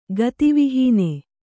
♪ gati vihīne